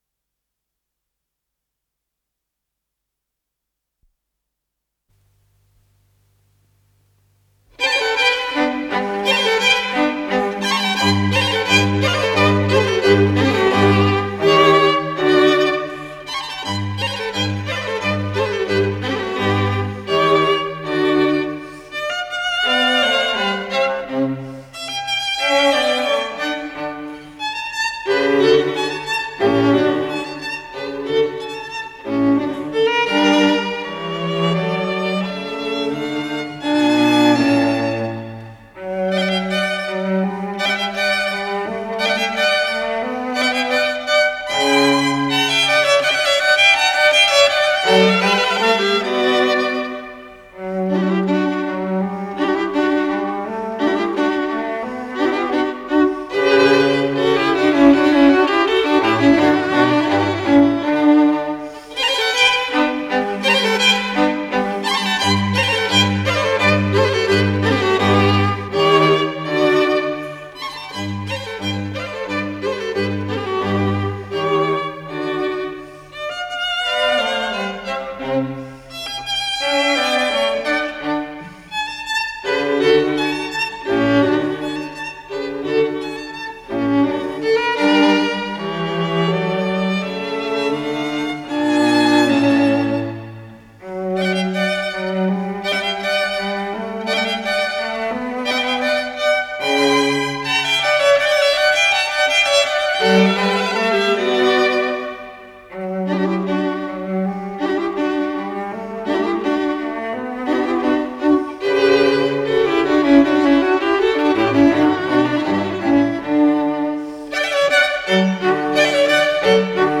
с профессиональной магнитной ленты
ВариантДубль моно